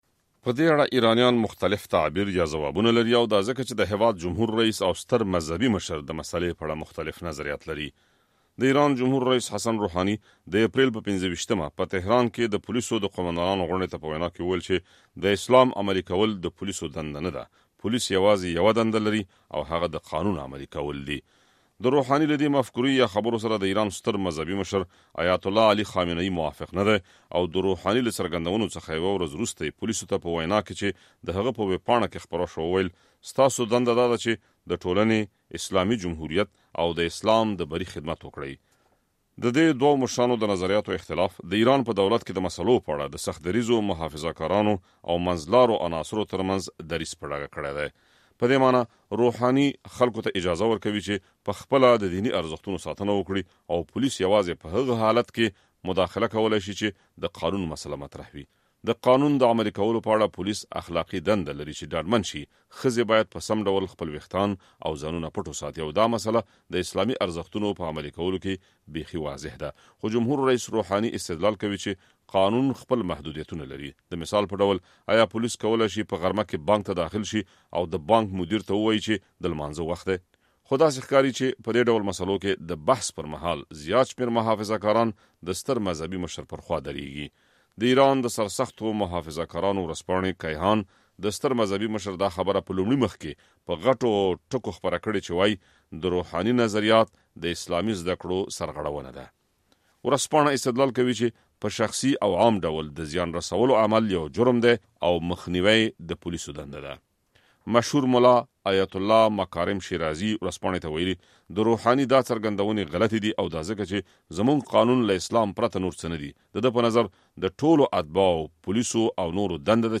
راپورونه